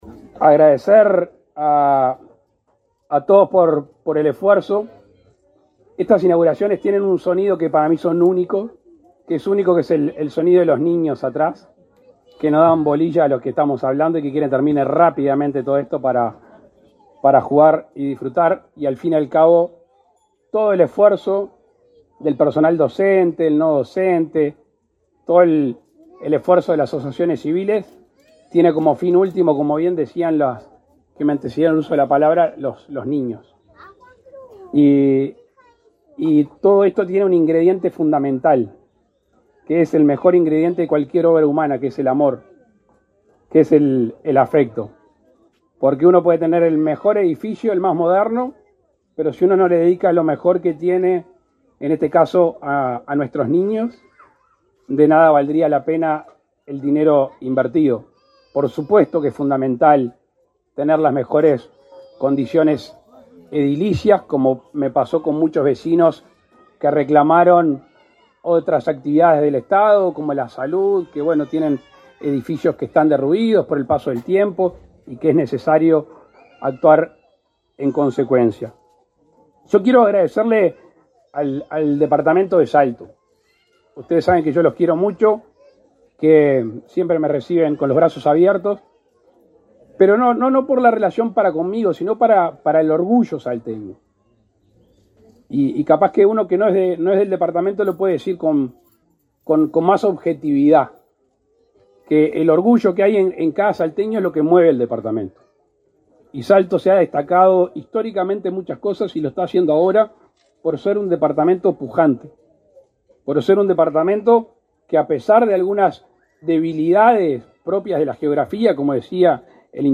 Palabras del presidente de la República, Luis Lacalle Pou
El presidente Lacalle Pou participó, este 20 de abril, en la inauguración del centro de atención a la infancia y la familia (CAIF) Nuevo Amanecer,